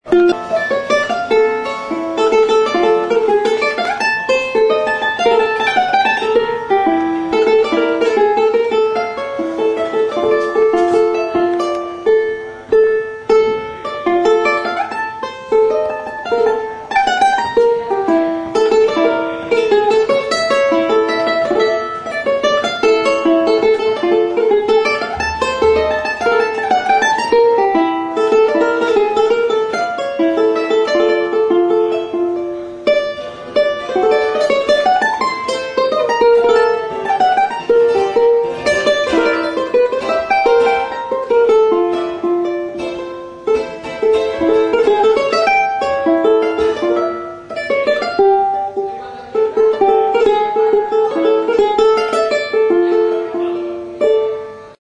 Cordófonos -> Pulsados (con dedos o púas)
TIMPLE
Zurezko erresonantzia kaxa eta kirtena dituen gitarra txiki modukoa da. Plastikozko 5 soka ditu.